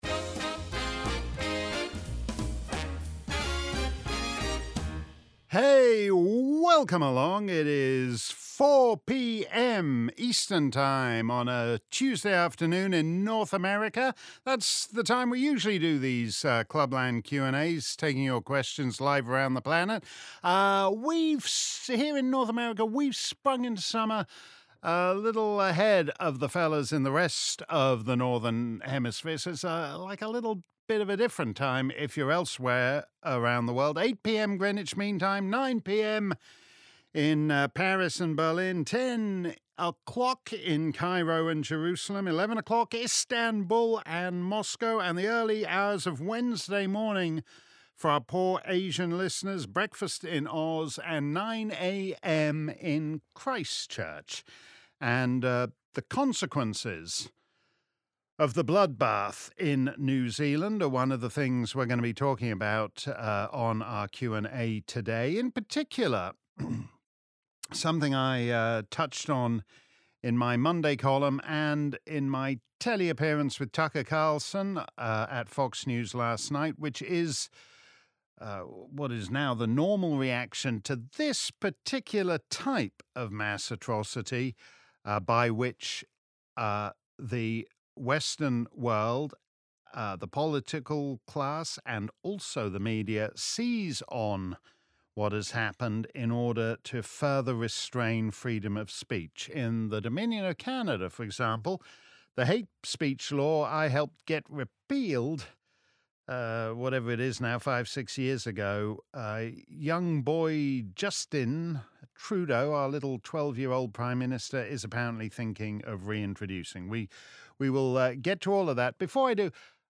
If you missed our livestream Clubland Q&A, here's the action replay.